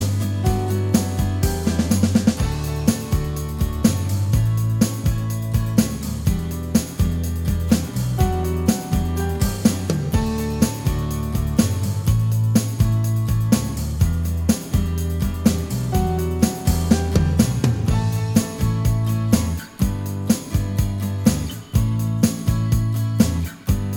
Minus Lead Guitar Indie / Alternative 3:20 Buy £1.50